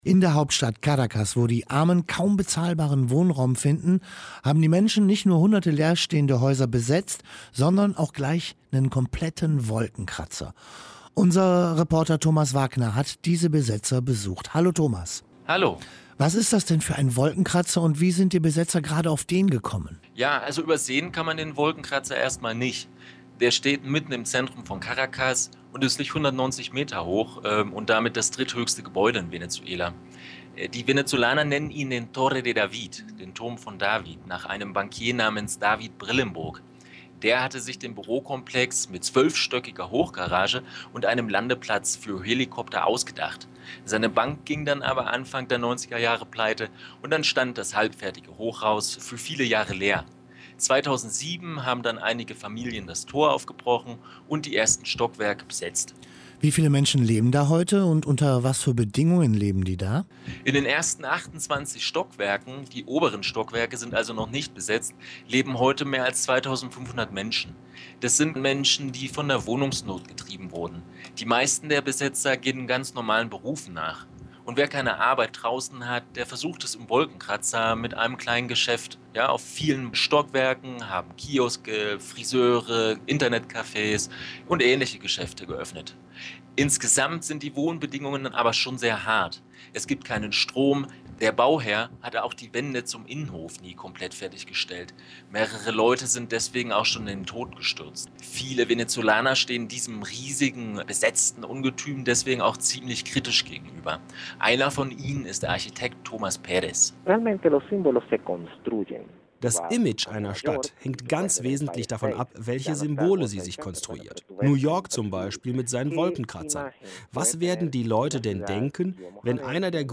In April 2012, German radio station WDR 5 interviewed me about my days in the Tower David.